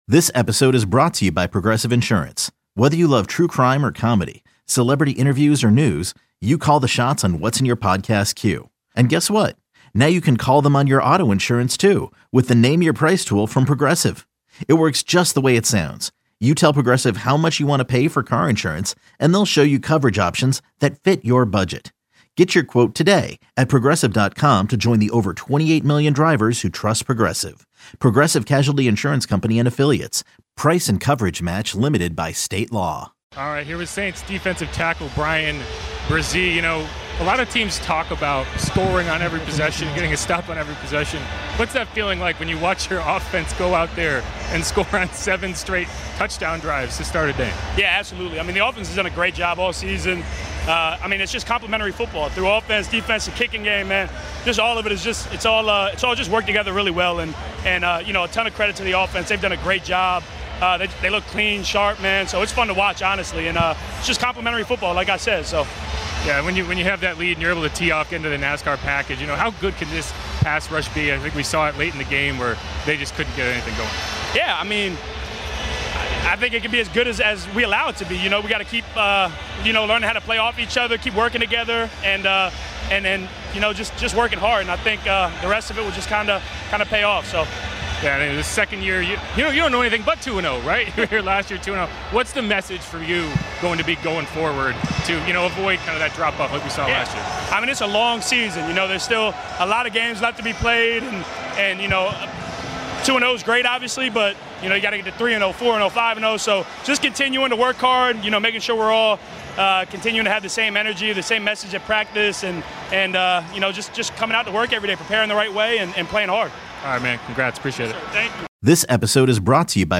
Saints Interviews Press Entercom Communications Corp NFL Sports American Football Saints FootBall WWL Conference
Bresee praised the team's complimentary football and pass rush.